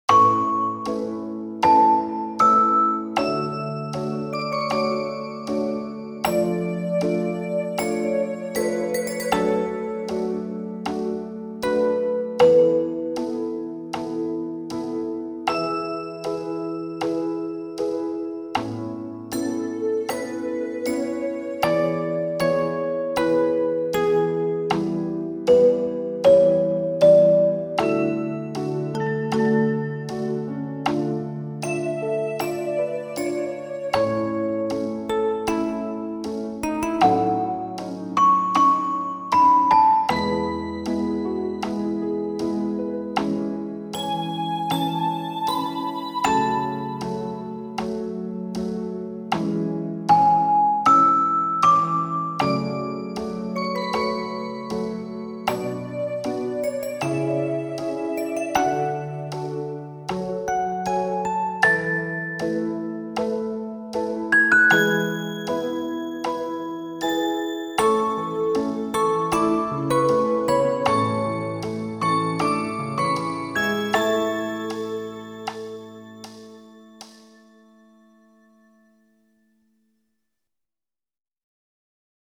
(音量注意)
なので、１小節おきに音色が違うと思います。
チクタクしてるのはメトロノームをぶっこわした音色ですｗ
ピアノ伴奏は一応、弾いてます。